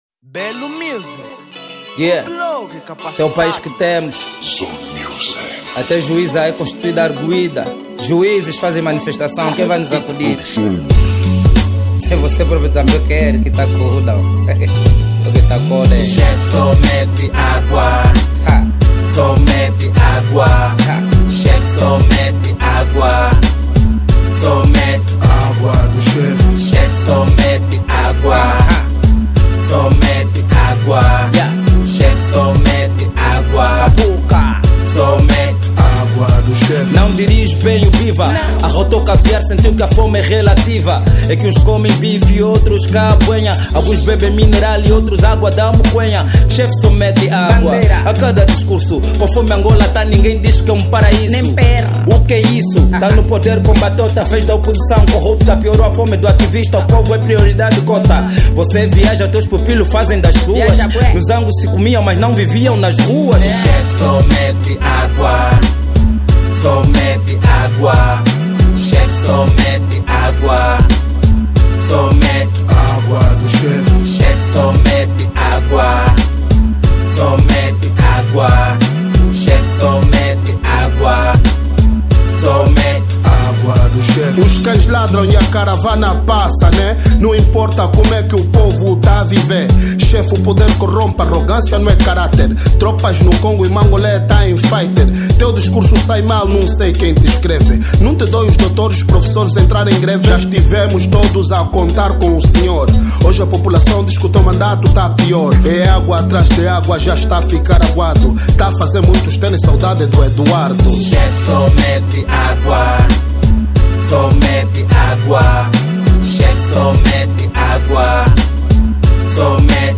Género: Rap